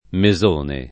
[ me @1 ne ]